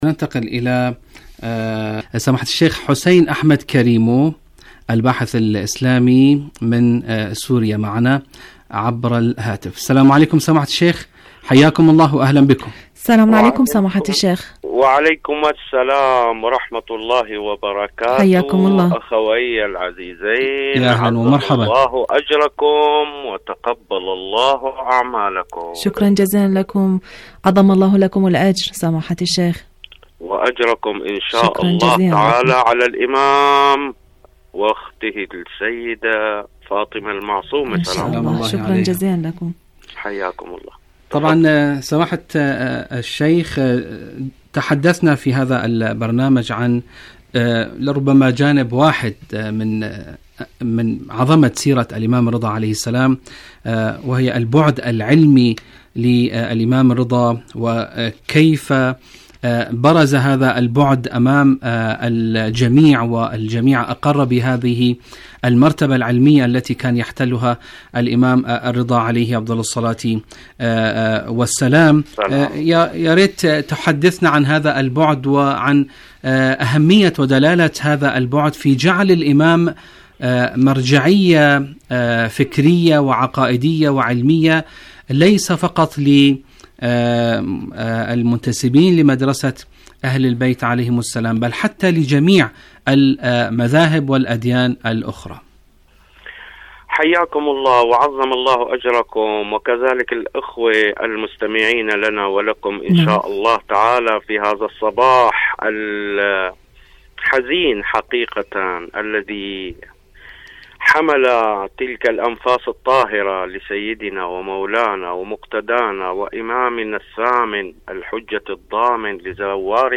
إذاعة طهران- شهادة الإمام الرضا عليه السلام: مقابلة إذاعية